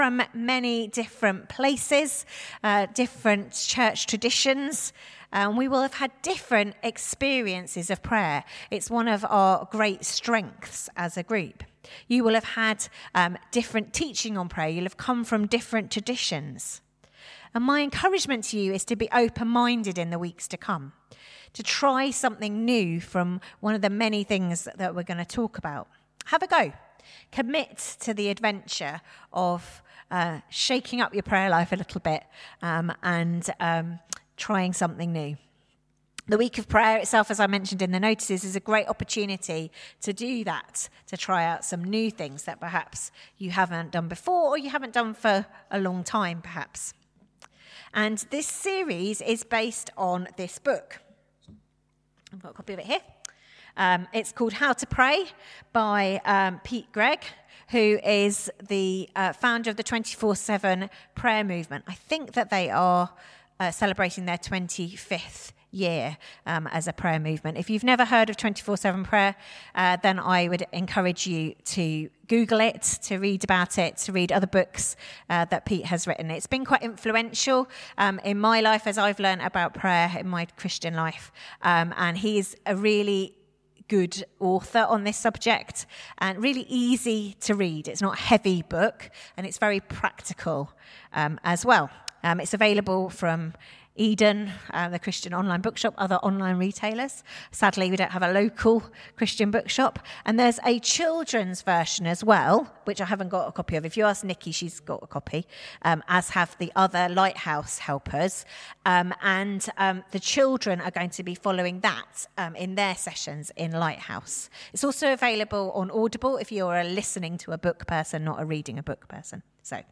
Sermon 15th September 2024 – Borehamwood Baptist